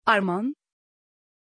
Pronunciation of Arman
pronunciation-arman-tr.mp3